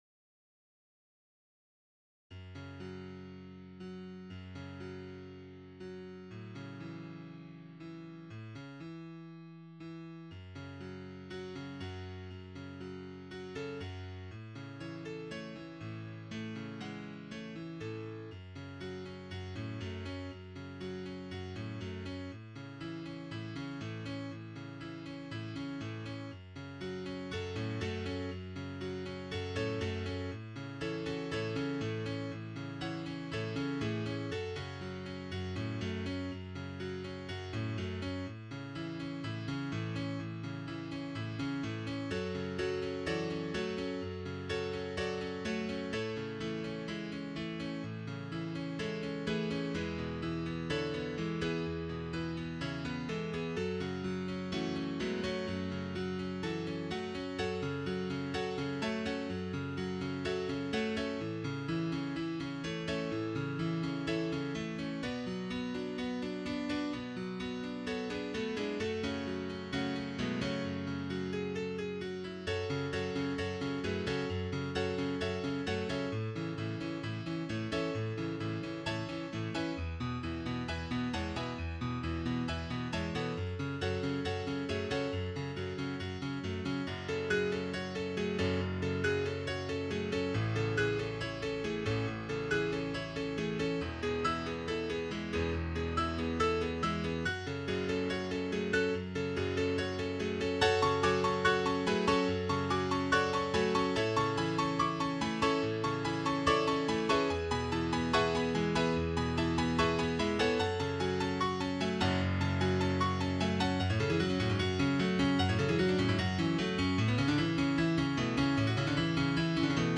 Title Riddle Of The Sphinx Opus # 50 Year 0000 Duration 00:03:18 Self-Rating 3 Description This piece reflects life, with its simplicity in the beginning, becoming louder and faster over time - and somewhat repetitive - before fading out. mp3 download wav download Files: mp3 wav Tags: Duet, Piano Plays: 1877 Likes: 0